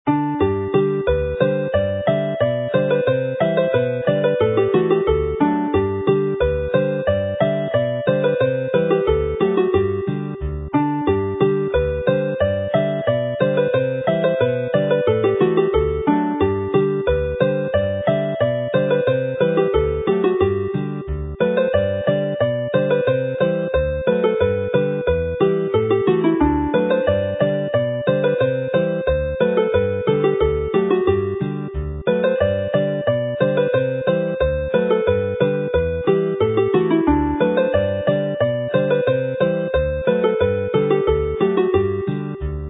is a jig that has been adapted to polka timing for this set,